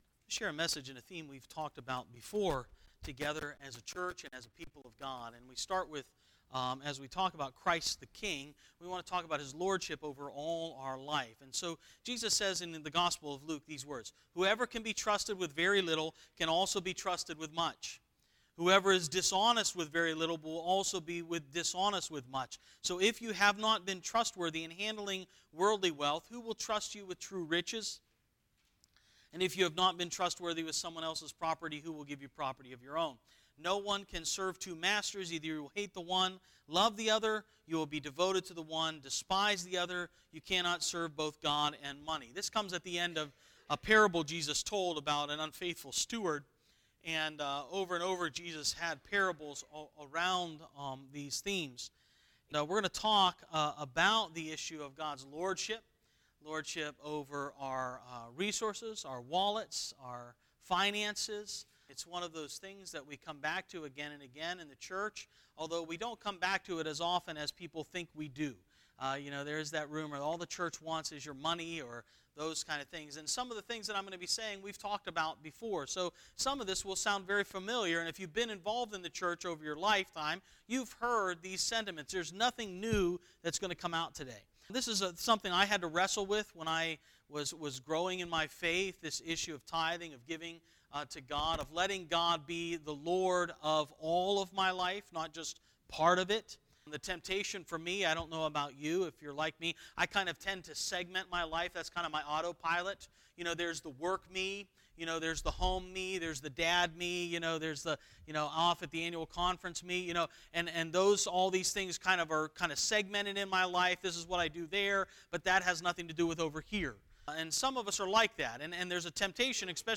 11-25-18 Sermon